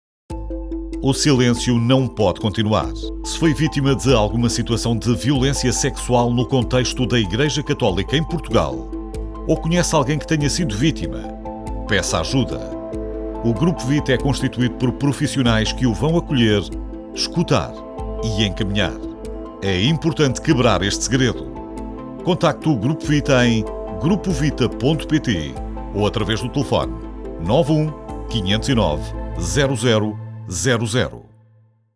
SPOT Rádio
SPOT-GRUPO-VITA.mp3